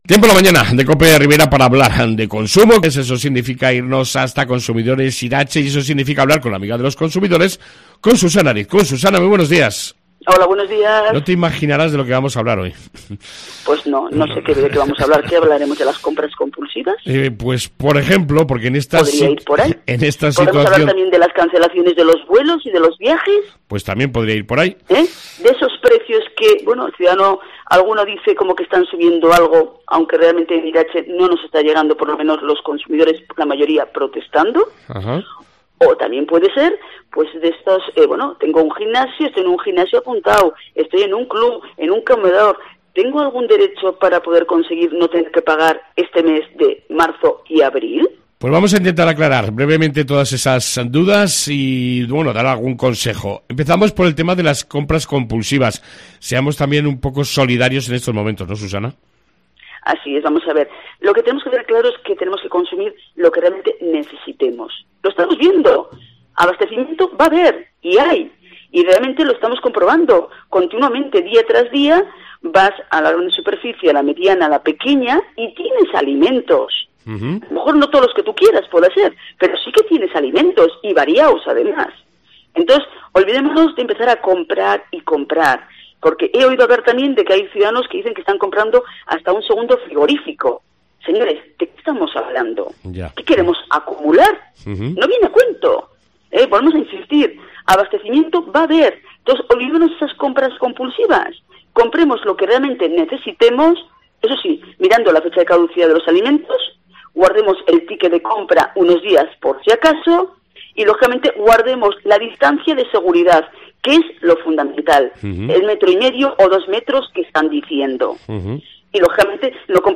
Entrevista con la asociación de consumidores Irache